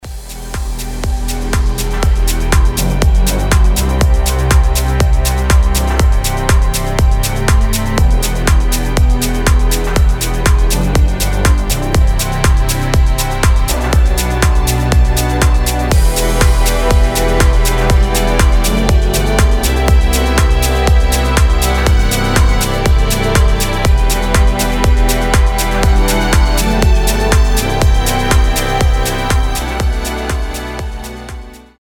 • Качество: 320, Stereo
Electronic
без слов
скрипка
deep progressive
progressive house
Melodic house
Вдохновляющая музыка в стиле мелодик прогрессив